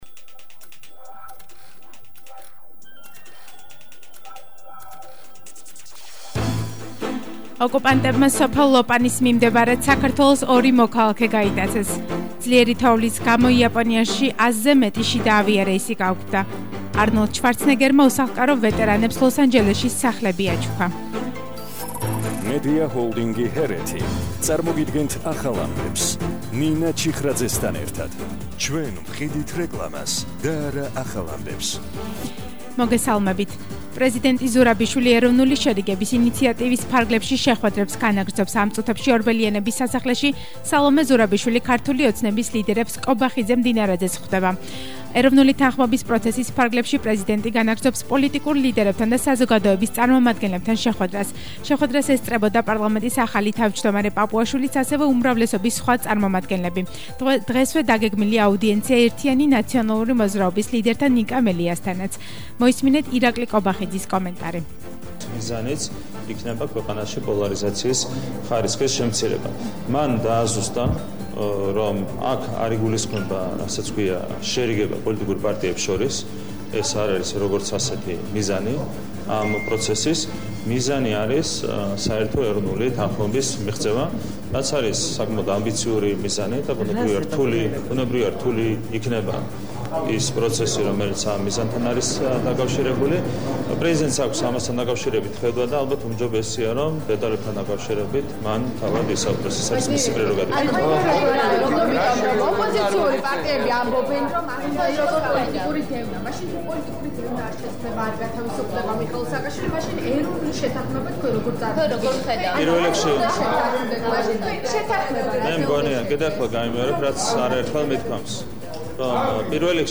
ახალი ამბები 14:00 საათზე – 27/12/21 - HeretiFM